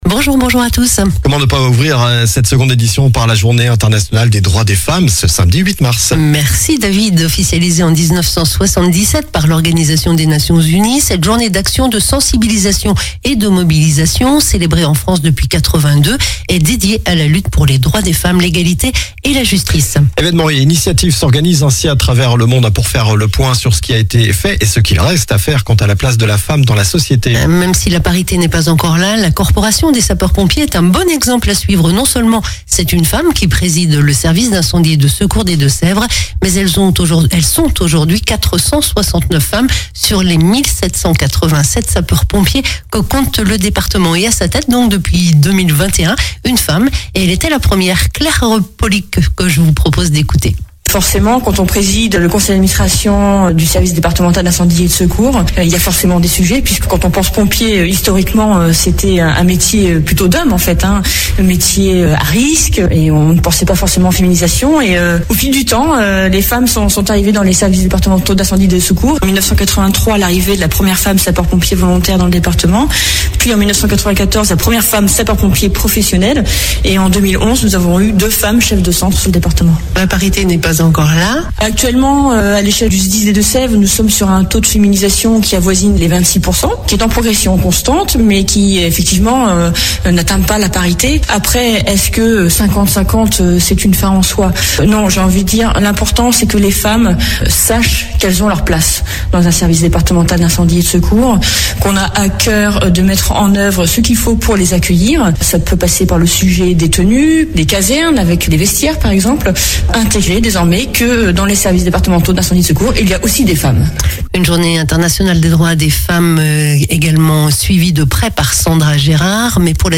Journal du samedi 8 mars